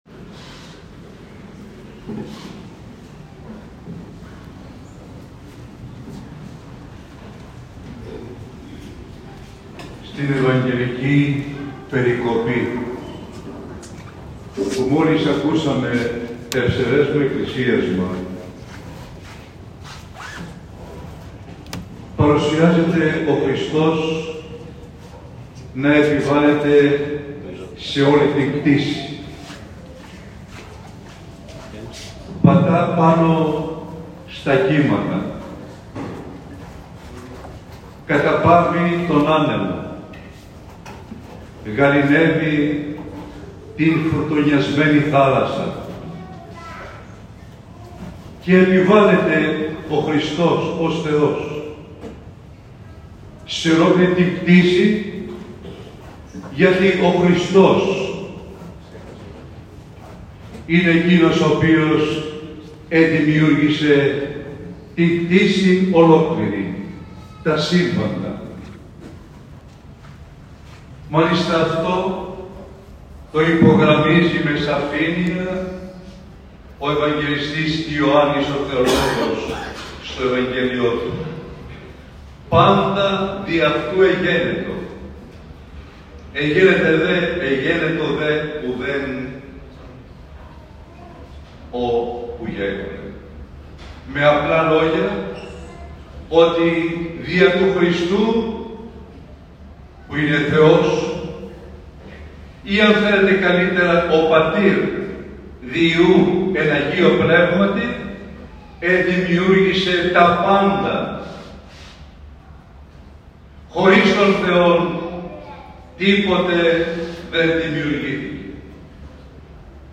Ο Μητροπολίτης Κασσανδρείας στο κήρυγμα του αναφέρθηκε στην ευαγγελική περικοπή.